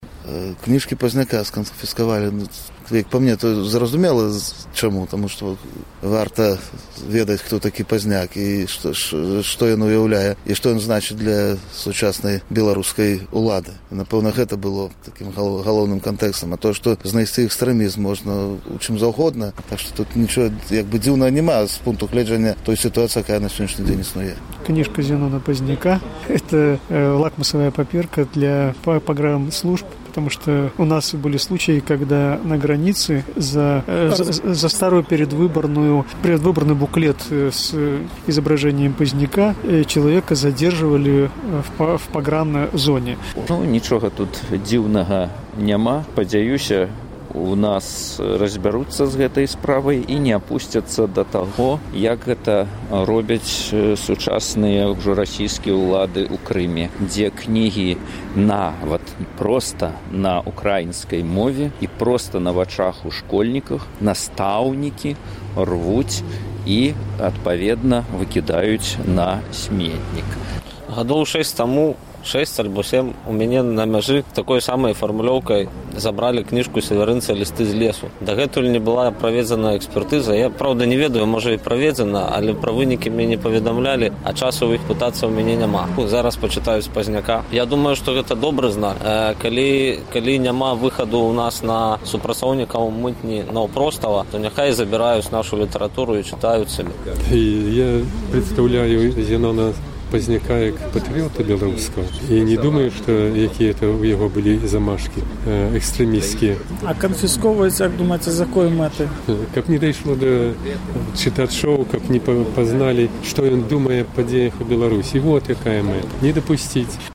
Адказваюць гарадзенцы
З такім пытаньнем наш карэспандэнт зьвяртаўся да гарадзенцаў